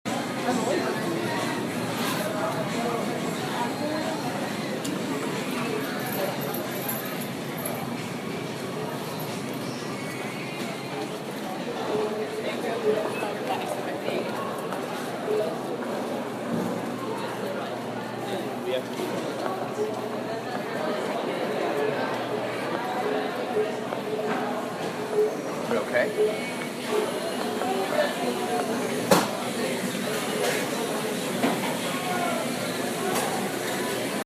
Field Recording-3
Student Center People Talking, Music over Loudspeakers, Footsteps, Kiosk Noises